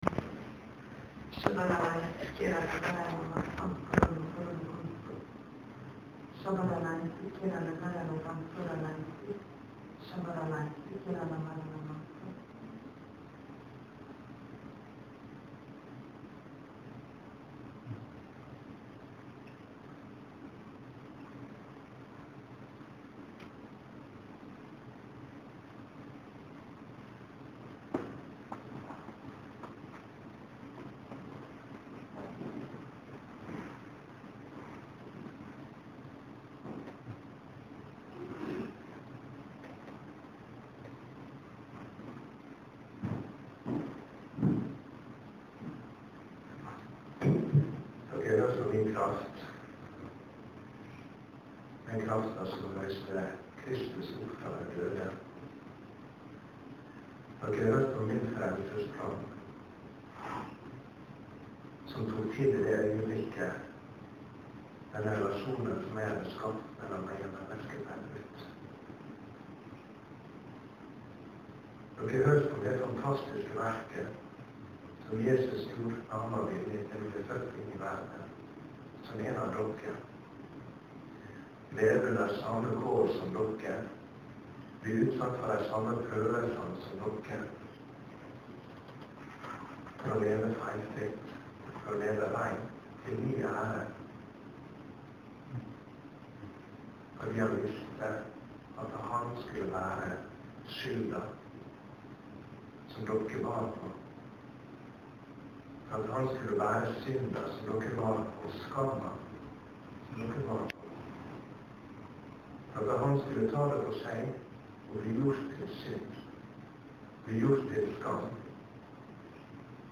(Tyding av tungetale, Ebeneser Volda, 2. påskedag, 5.4.10.)